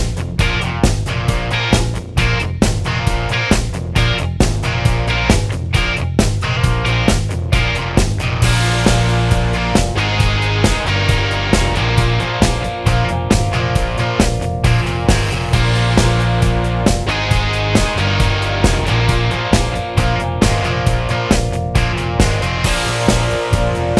no Backing Vocals Rock 4:47 Buy £1.50